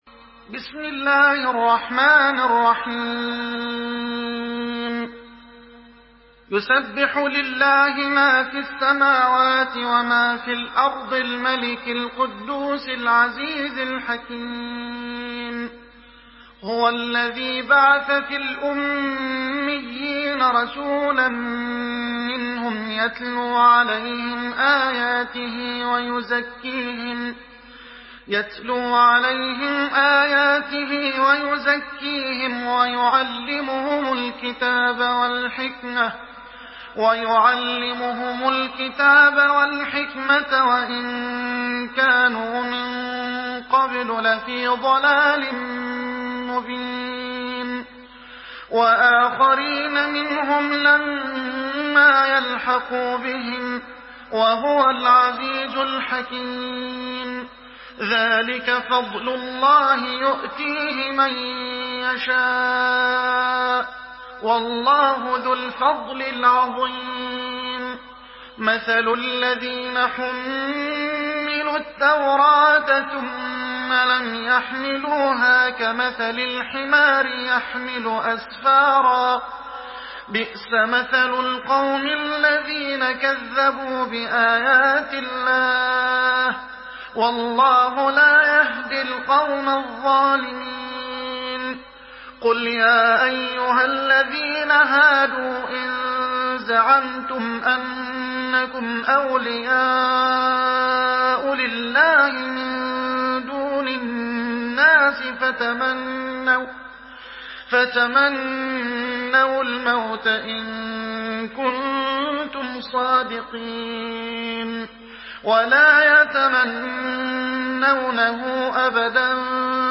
سورة الجمعة MP3 بصوت محمد حسان برواية حفص
مرتل